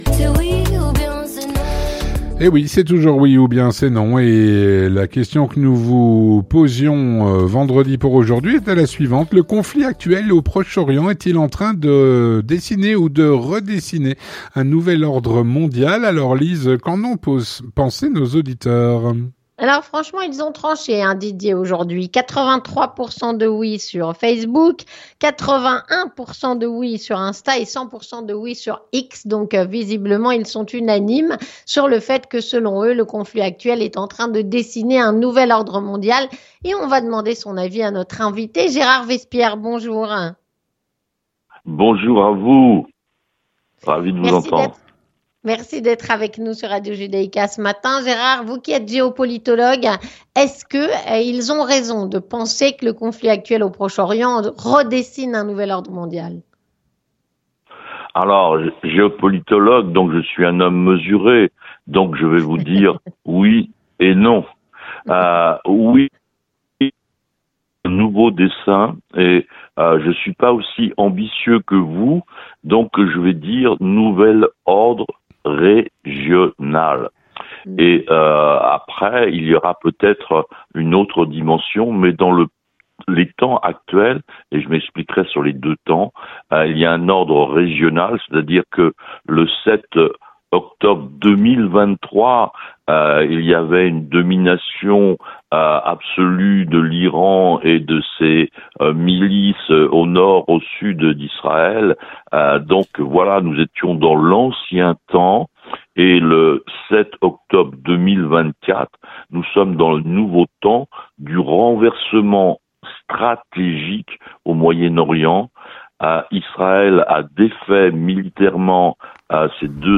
Écoutons l'avis de notre invité...